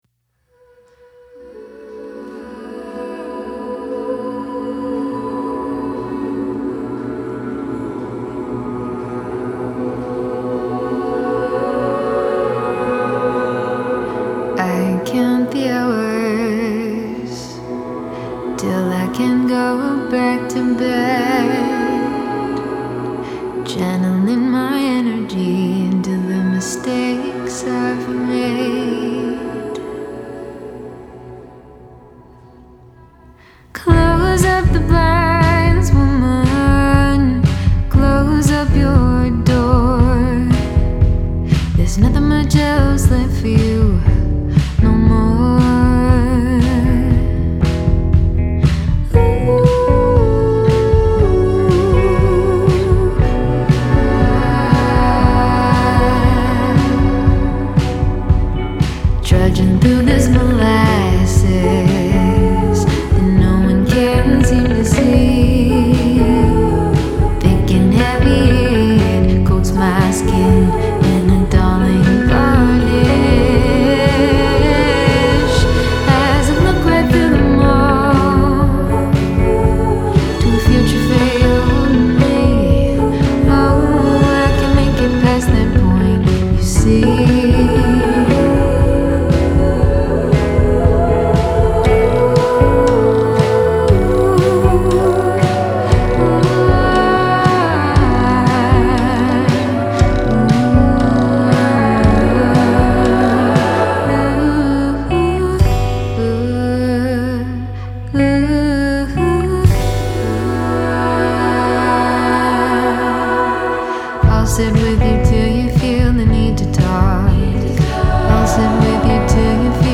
Recorded in Victoria, BC